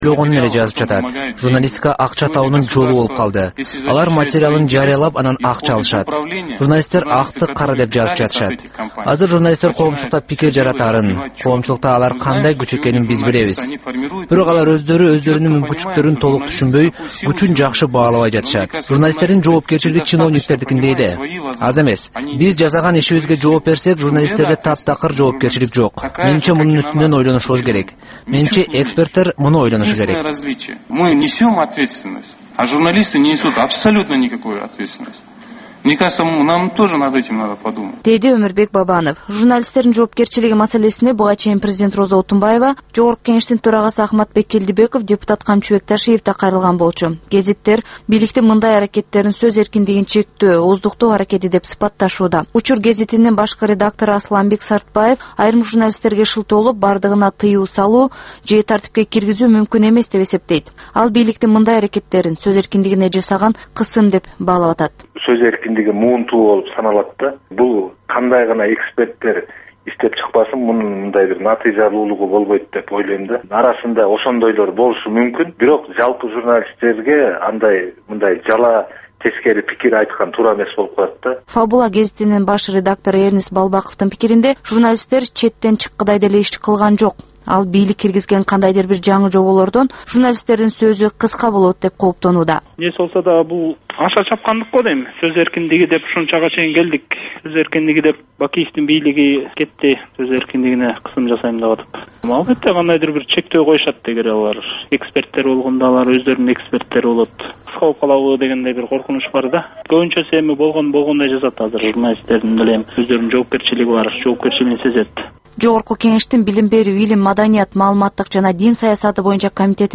Бул түшкү үналгы берүү жергиликтүү жана эл аралык кабарлар, ар кыл орчун окуялар тууралуу репортаж, маек, талкуу, кыска баян жана башка оперативдүү берүүлөрдөн турат. "Азаттык үналгысынын" бул чак түштөгү алгачкы берүүсү Бишкек убакыты боюнча саат 12:00ден 12:15ке чейин обого чыгарылат.